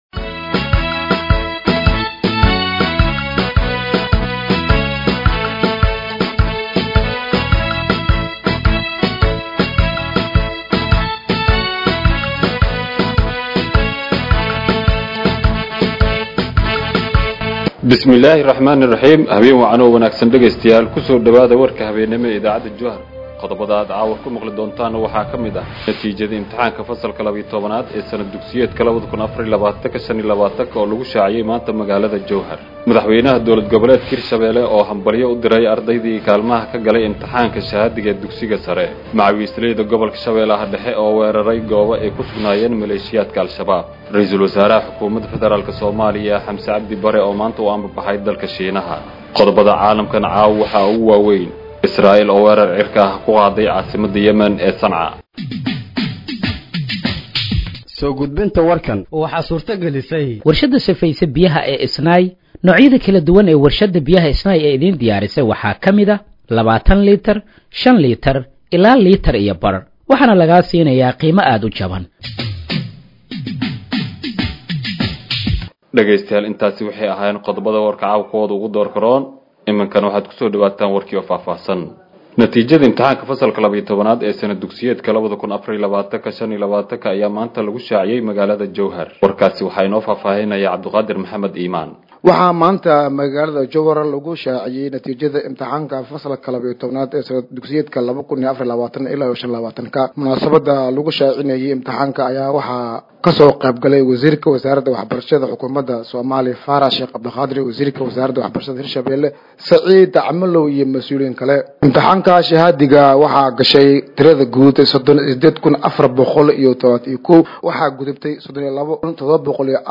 Dhageeyso Warka Habeenimo ee Radiojowhar 24/08/2025